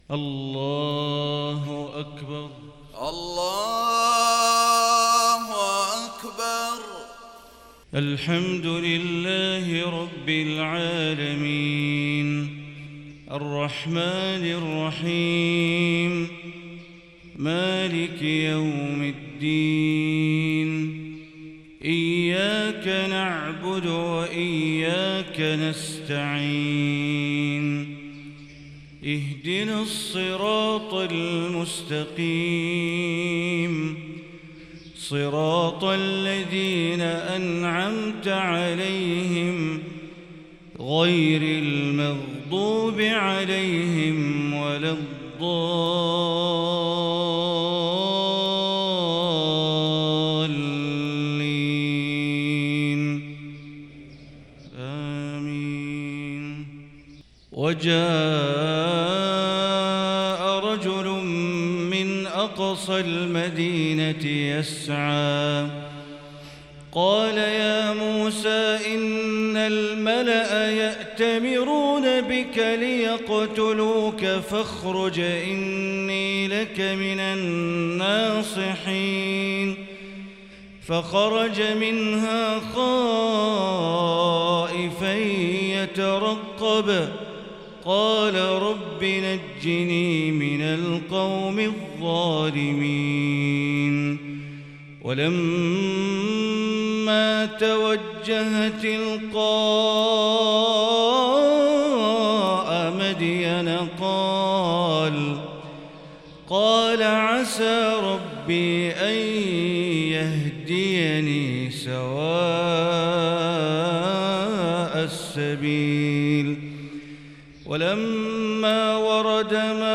فجرية جميلة من سورة القصص للشيخ بندر بليلة 9 جمادى الآخرة 1444هـ